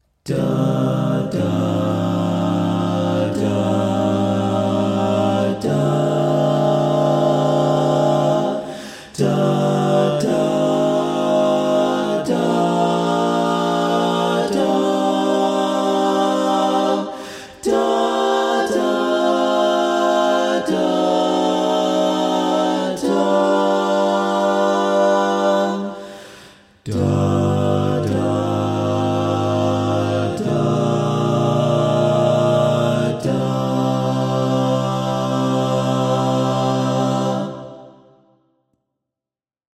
Key written in: C Minor
How many parts: 4
Type: Barbershop
All Parts mix:
Learning tracks sung by